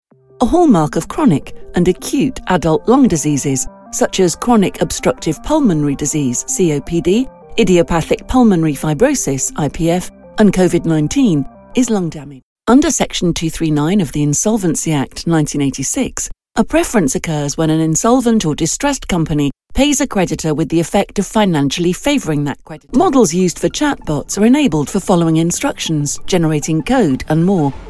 Medical Voice Over Artists | Voice Fairy